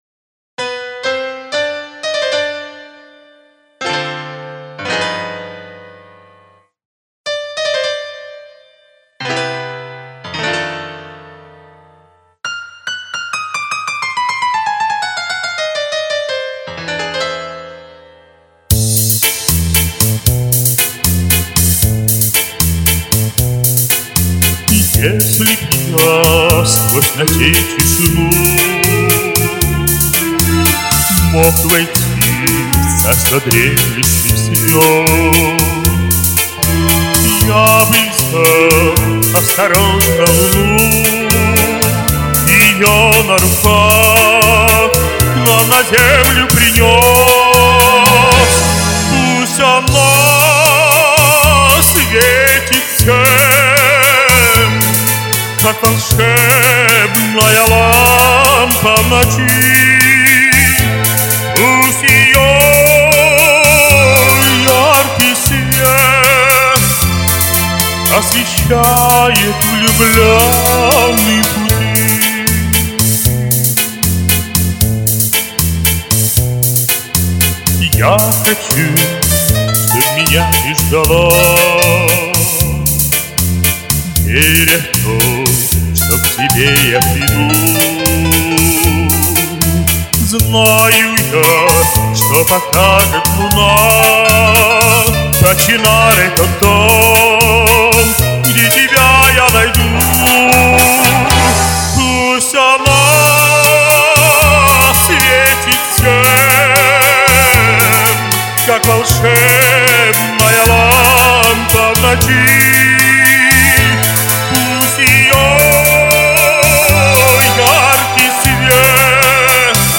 Равноценные прекрасные голоса и исполнения!